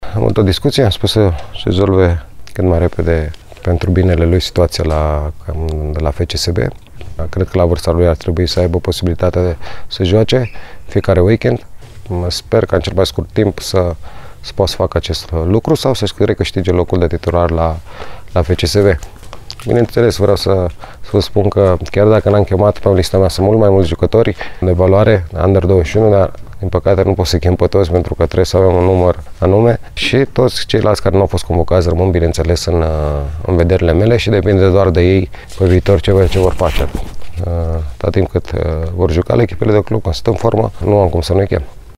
Într-o declarație pentru canalul video al FRF, selecționerul reprezentativei de tineret a mai spus că a chemat jucători care evoluează constant la cluburile lor, în această perioadă, acesta fiind motivul pentru care l-a ”ocolit” pe arădeanul Adrian Petre – un convocat regulat în perioada anterioară: